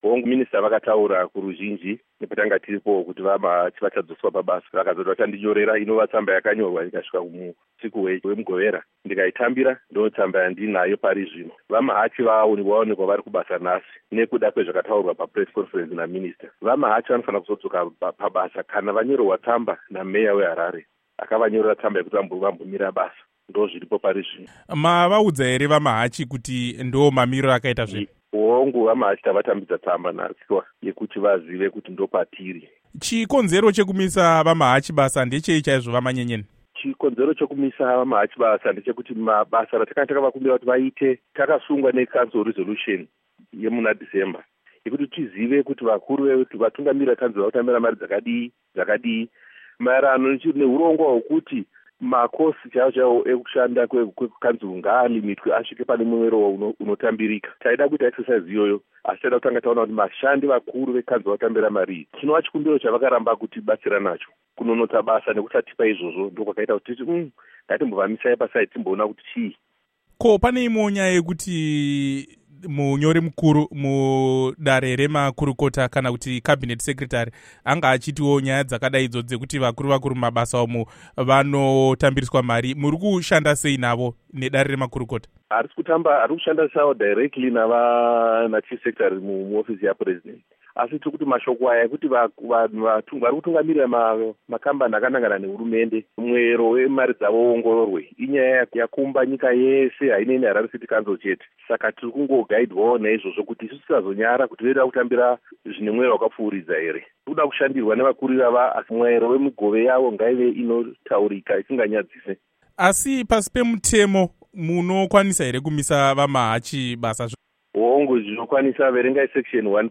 Hurukuro naVaBernard Manyenyeni